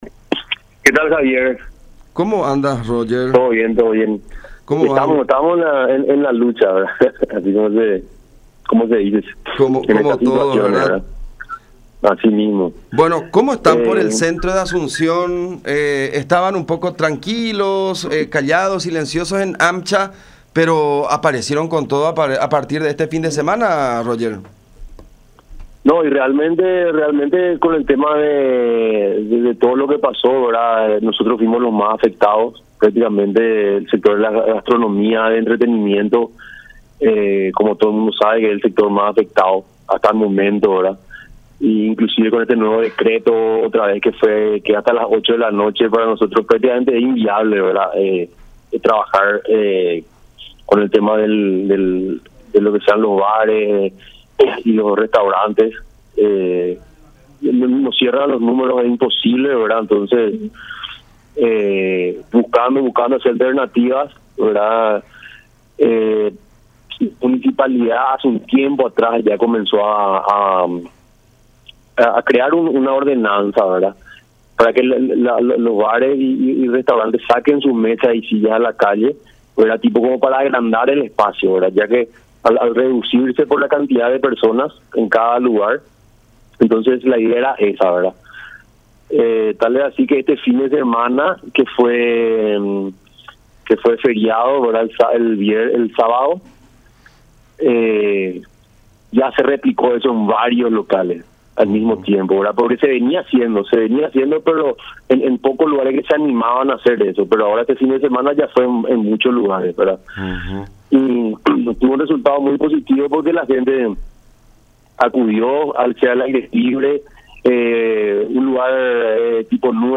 en contacto con La Unión R800 AM